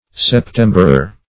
Septemberer \Sep*tem"ber*er\, n.